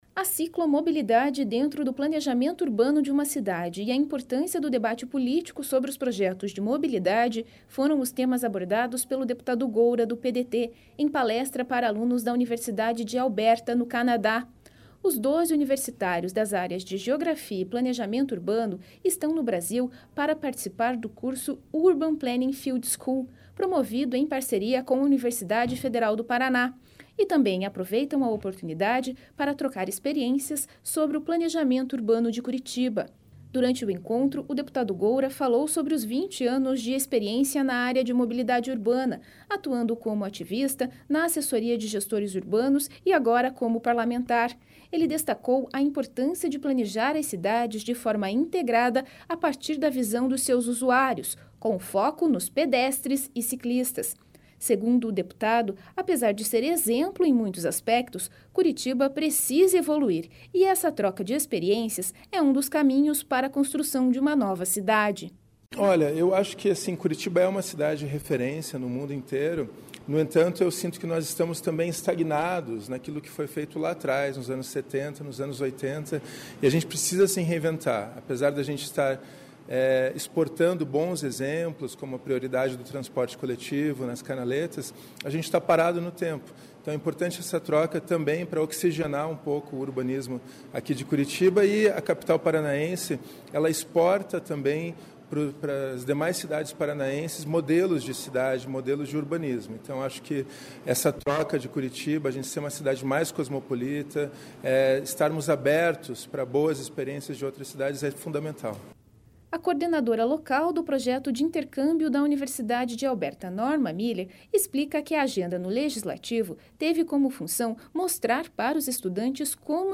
A ciclomobilidade dentro do planejamento urbano de uma cidade e a importância do debate político sobre os projetos de mobilidade foram os temas abordados pelo deputado Goura (PDT) em palestra para alunos da Universidade de Alberta, no Canadá.